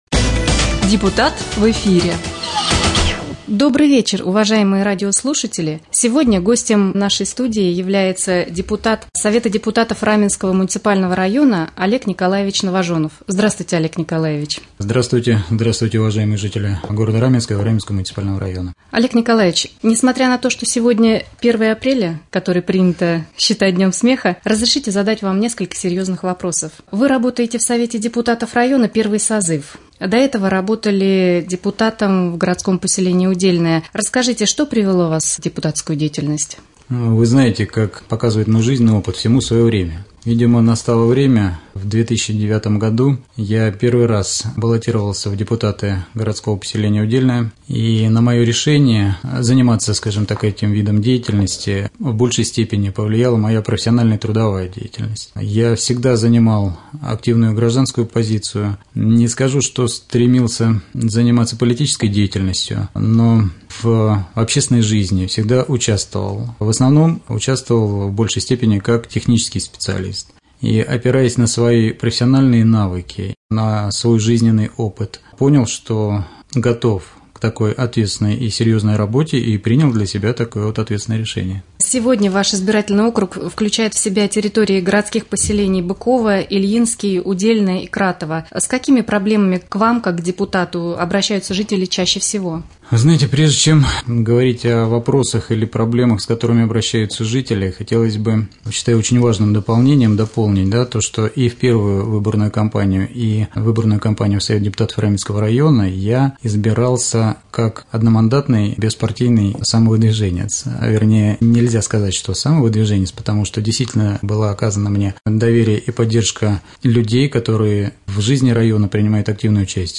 Гость студии депутат районного совета депутатов Олег Николаевич Новожонов.